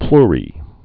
(plrē)